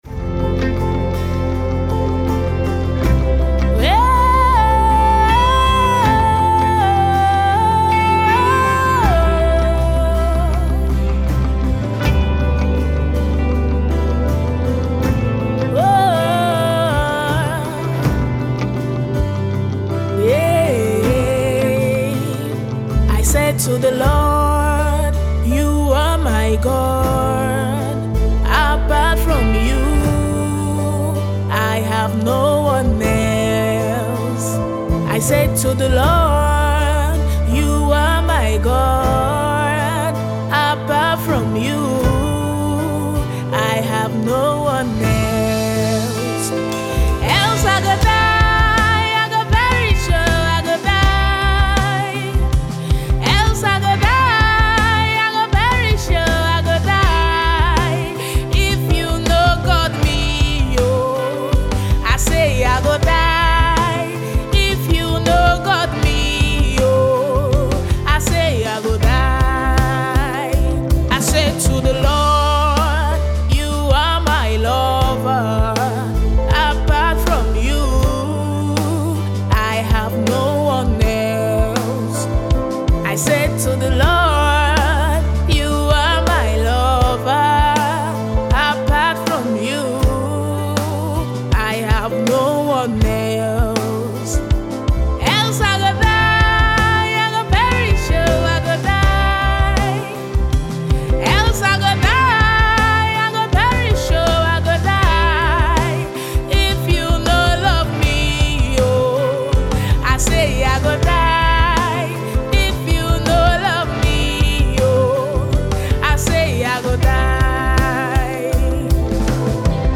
July 14, 2024 Admin Music 2